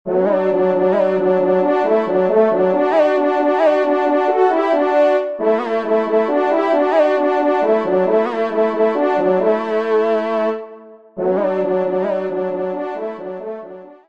Genre : Musique Religieuse pour  Quatre Trompes ou Cors
Pupitre 2° Trompe